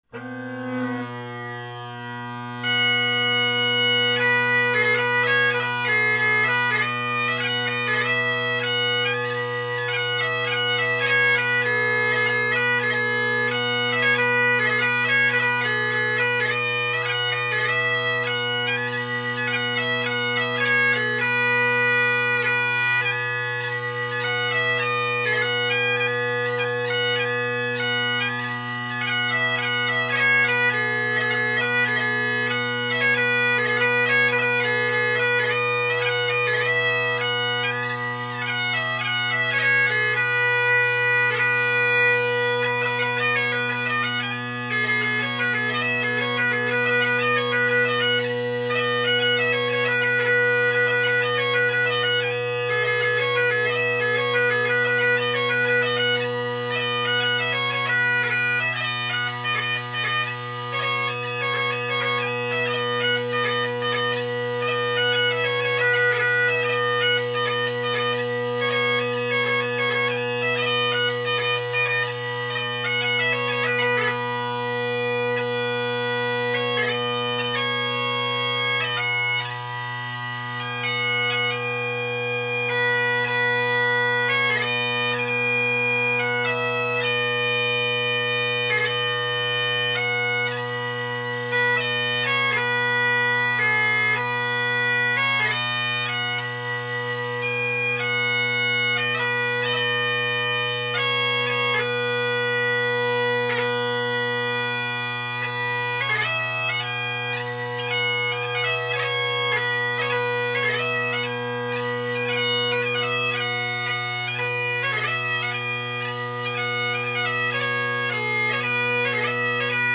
This chanter is louder than any other I’ve recorded, so pay attention to your speaker/headphone volume.
My Band’s Old Medley – facing away from the mic
The pipes played are my Colin Kyo bagpipe with Ezee tenors and short inverted Ezee bass.